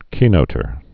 (kēnōtər)